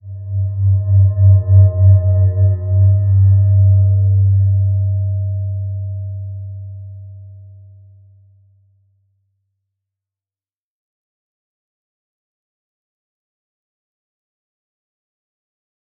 Slow-Distant-Chime-G2-mf.wav